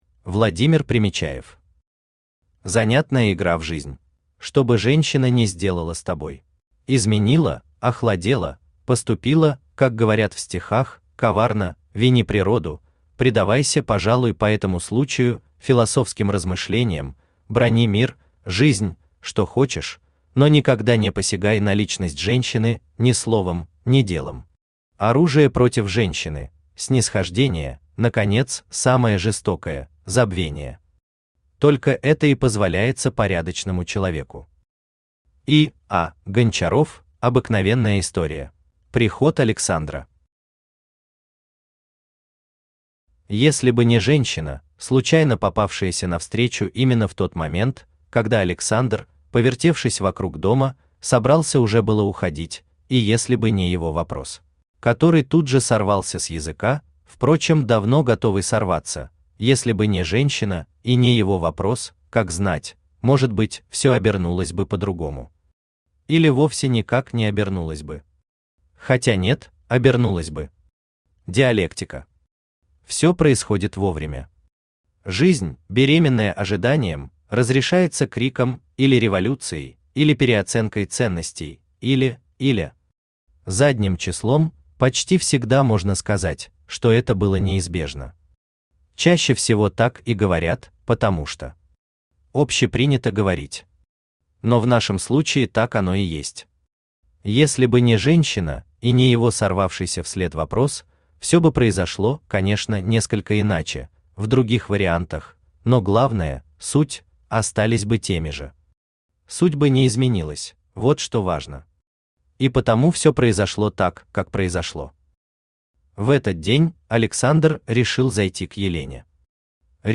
Aудиокнига Занятная игра в жизнь Автор Владимир Примечаев Читает аудиокнигу Авточтец ЛитРес.